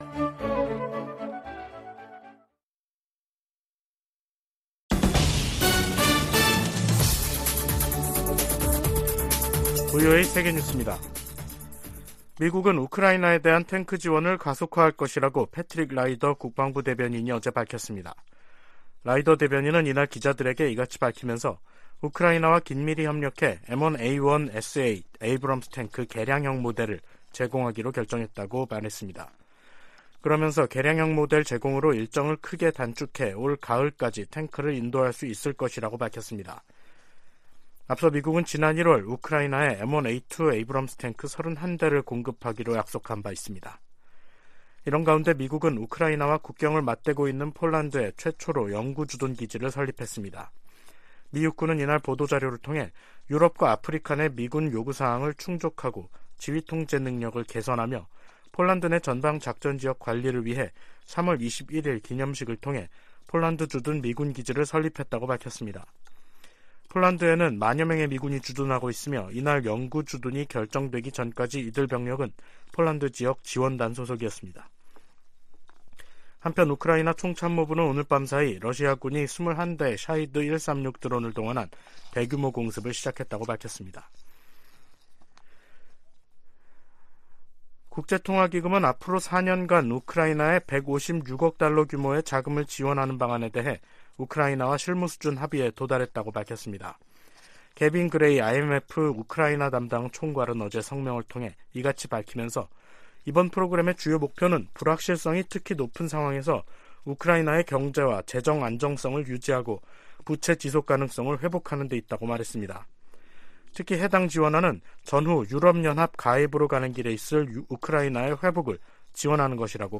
VOA 한국어 간판 뉴스 프로그램 '뉴스 투데이', 2023년 3월 22일 2부 방송입니다. 북한이 또 다시 순항미사일 여러 발을 동해상으로 발사했습니다. 미국 정부는 모의 전술핵 실험에 성공했다는 북한의 주장에 우려를 표했습니다.